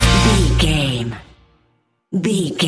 Ionian/Major
E♭
acoustic guitar
electric guitar
drums
bass guitar
country rock